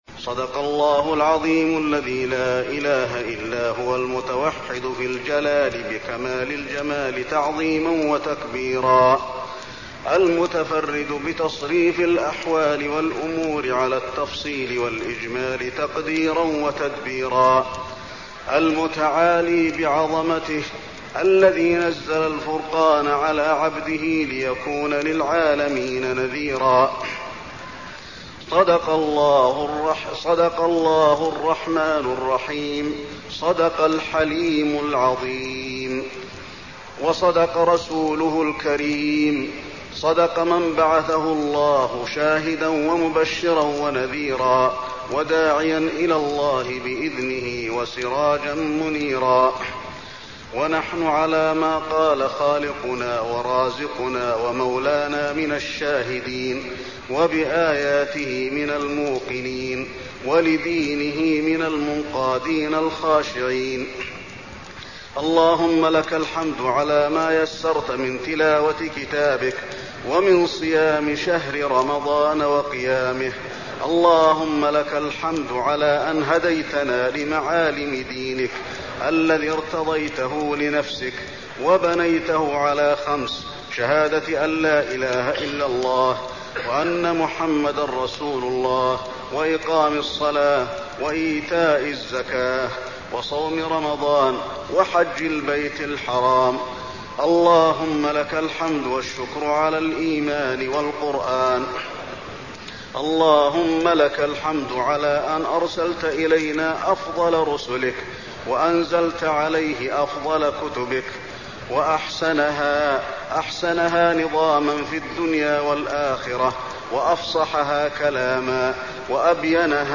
الدعاء
المكان: المسجد النبوي الدعاء